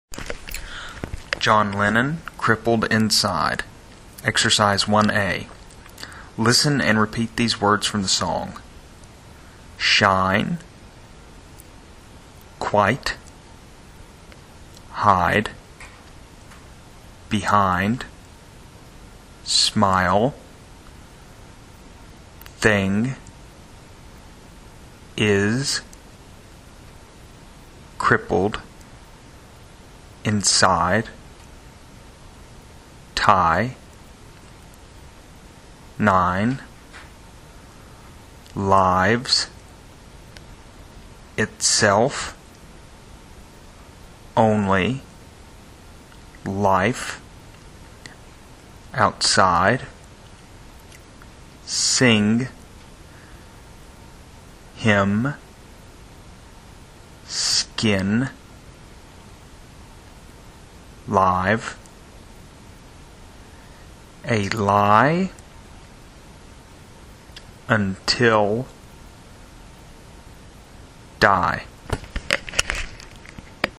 Ressource utilisée: piste son enregistrée par l’assistant sur le baladeur et CD.